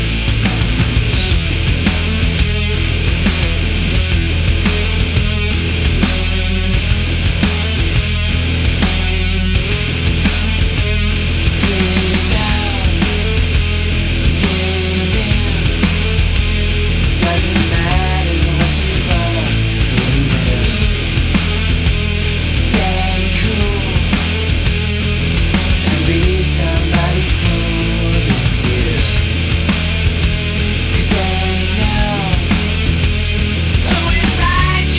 Жанр: Рок / Альтернатива